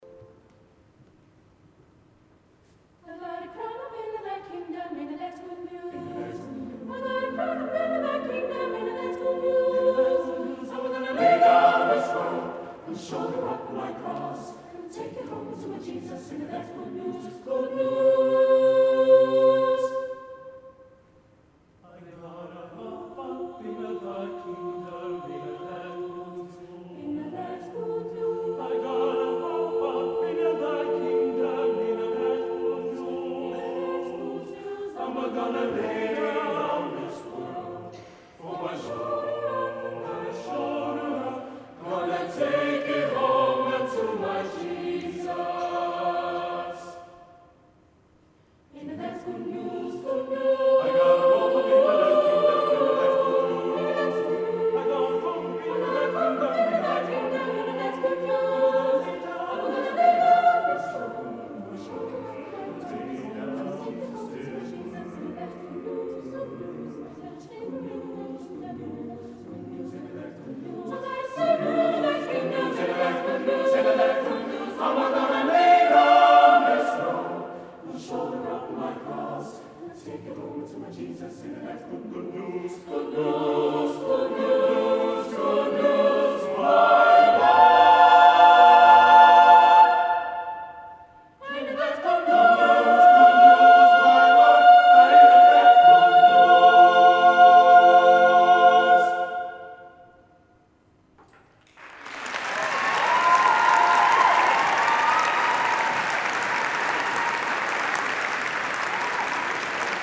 Recordings from The Big Sing National Final.
The Sweet Sixteen Aorere College Ain'a That Good News Loading the player ...